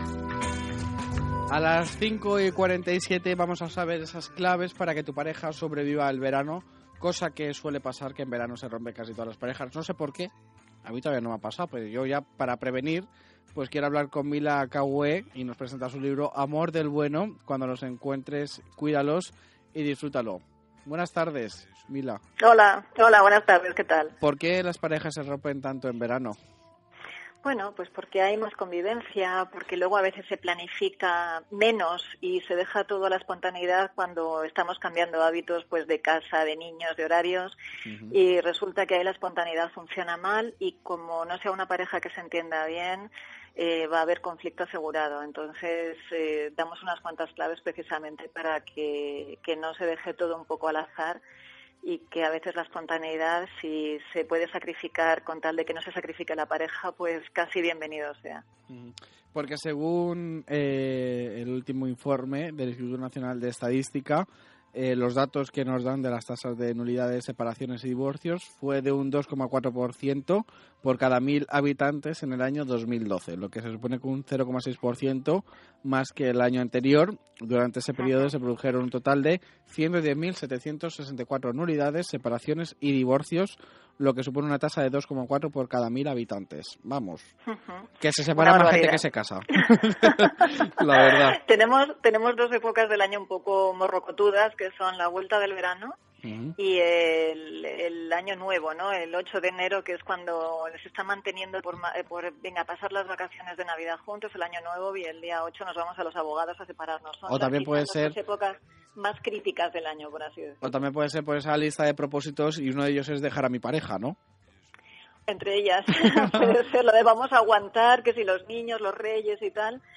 Una entrevista informal, desenfadada… en la que hablamos de esa intimidad y sufrimiento emocional que es imprescindible transformar en experiencias positivas.